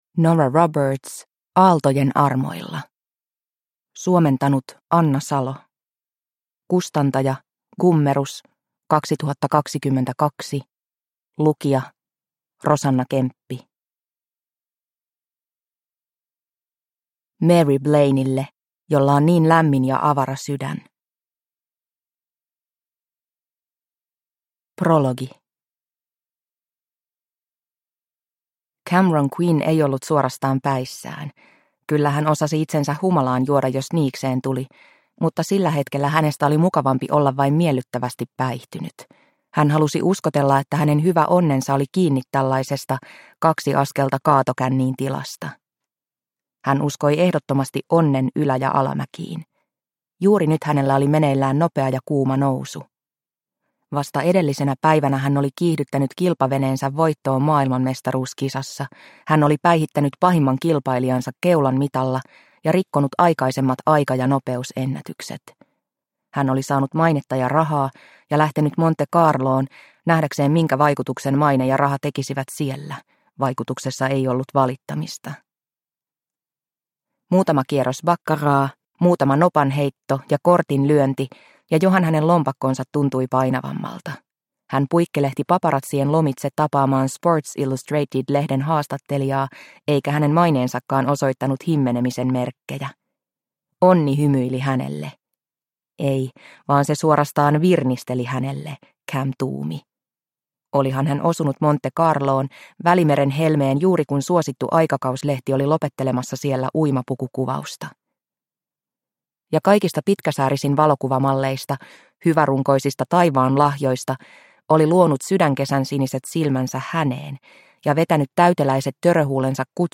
Aaltojen armoilla – Ljudbok – Laddas ner